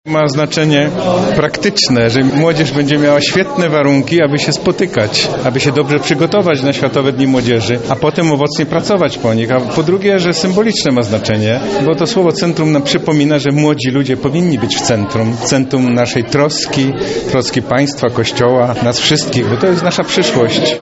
Cieszymy się, że to nowe centrum właśnie zafunkcjonowało, mówił arcybiskup Stanisław Budzik.